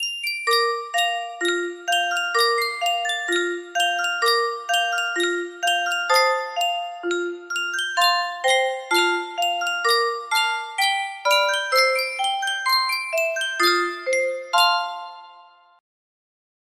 Sankyo Music Box - You're a Grand Old Flag FFF music box melody
Full range 60